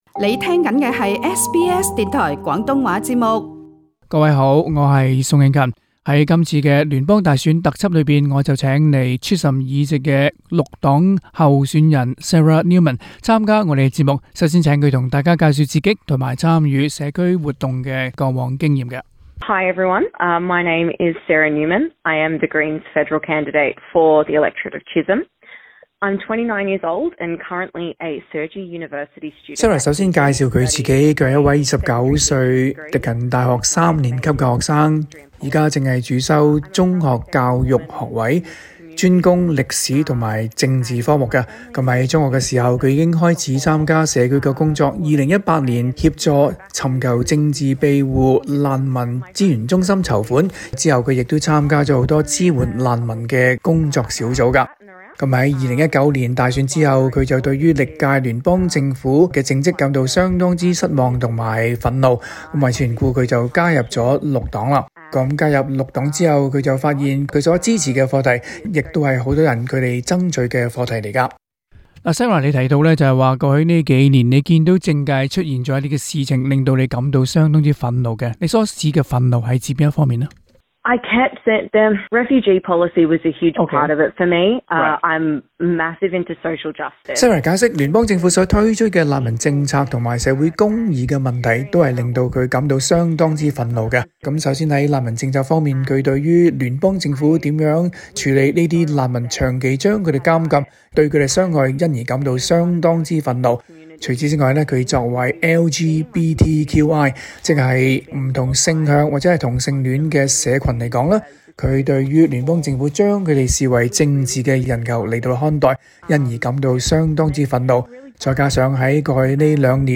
訪問